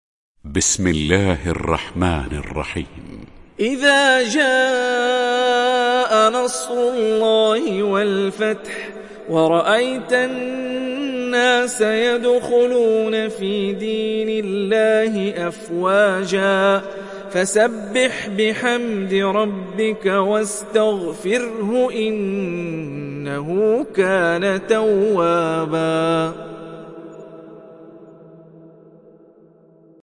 Surat An Nasr mp3 Download Hani Rifai (Riwayat Hafs)